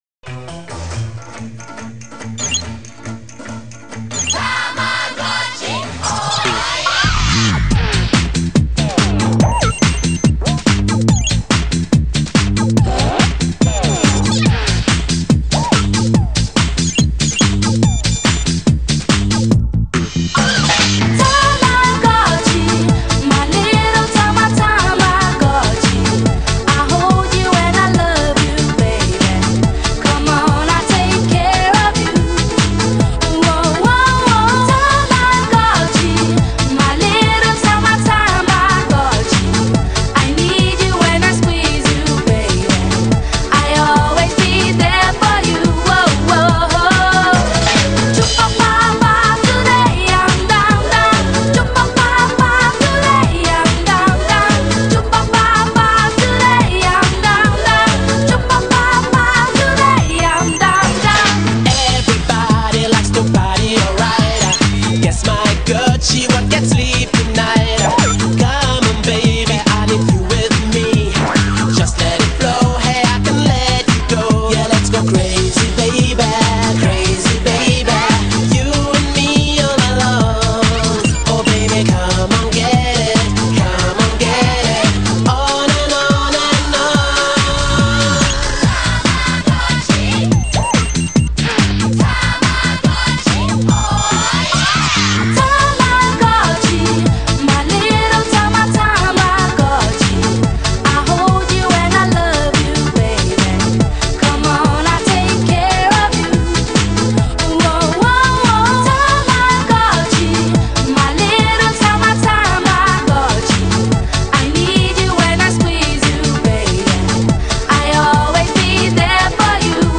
BPM71-142
Audio QualityCut From Video